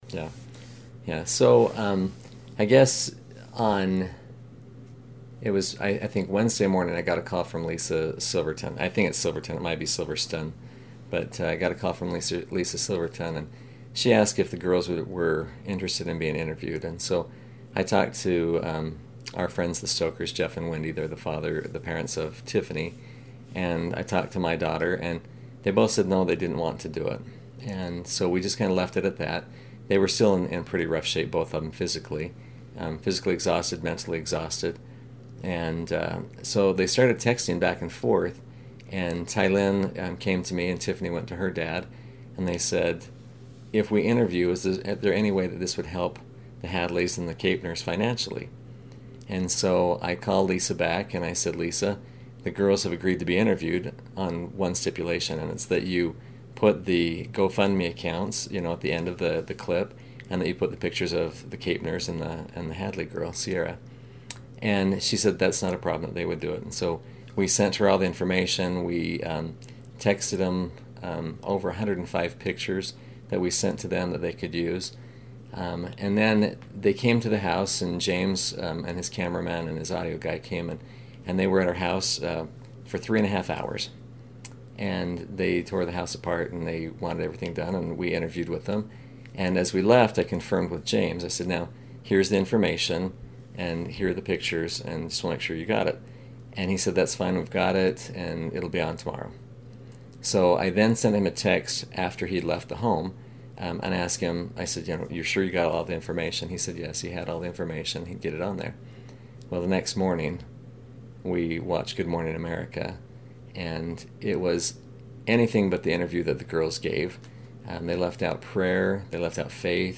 He says all faith aspects of their survival story were edited out of the first national media outlet to tell their story. Here is his interview.